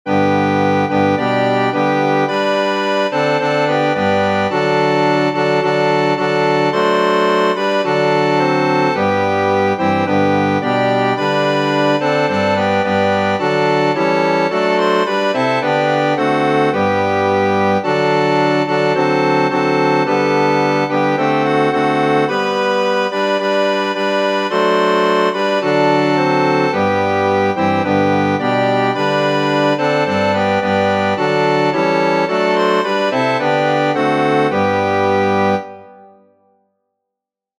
Flauta Letra: PowerPoint , PDF Música: PDF , MIDI , MXL 1.